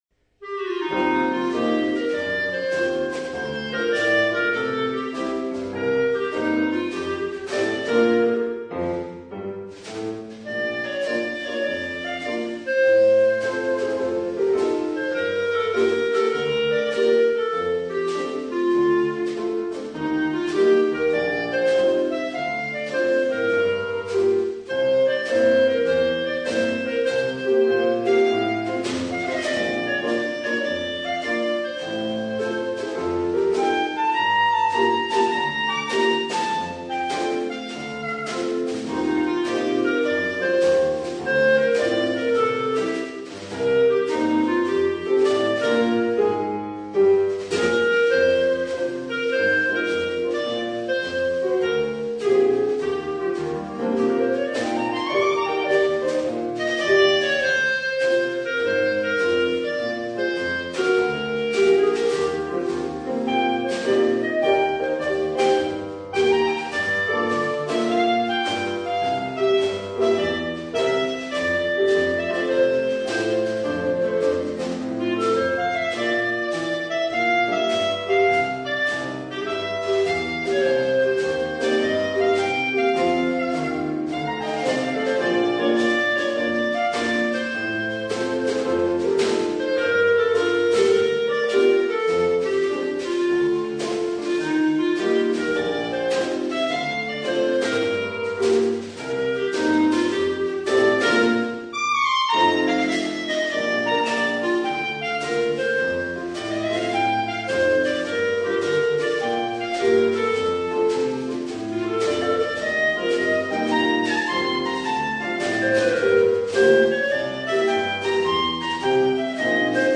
Per clarinetto e pianoforte
Uno swing per clarinetto e pianoforte.